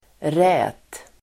Uttal: [rä:t]